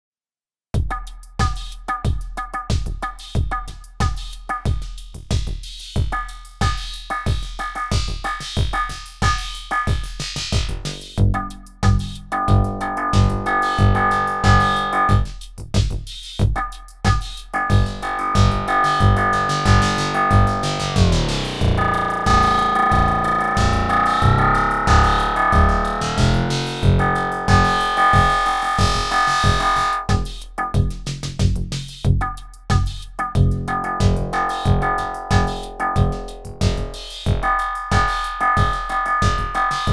Off-topic, but happened to be recording something else and got curious about resonator: I think that v. low delay times (around 1/128), high feedback (90s) and playing with filter gets one pretty close to the “wave guide” resonator controls on Volca Drum at least from what I’d seen in reviews about it?
Starting dry and then moving those three settings (and nothing else) around here: